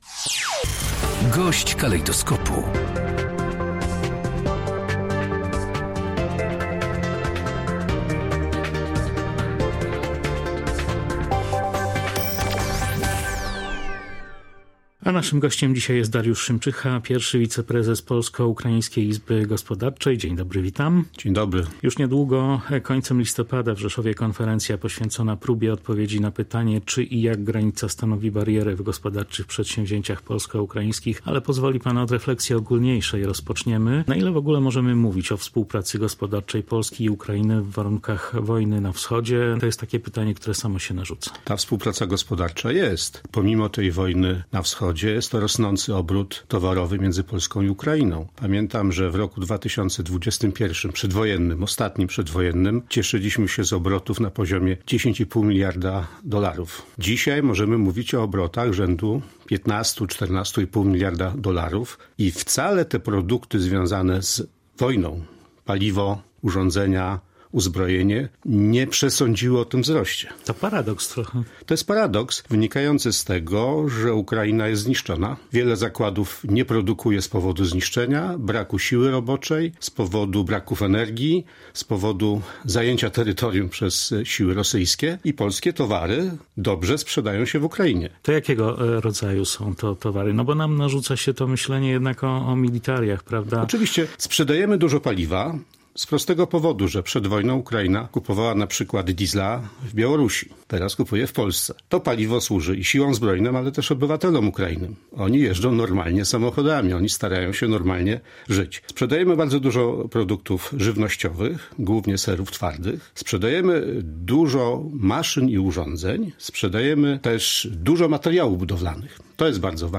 Pomimo wojny trwa współpraca gospodarcza Polski i Ukrainy • Gość dnia • Polskie Radio Rzeszów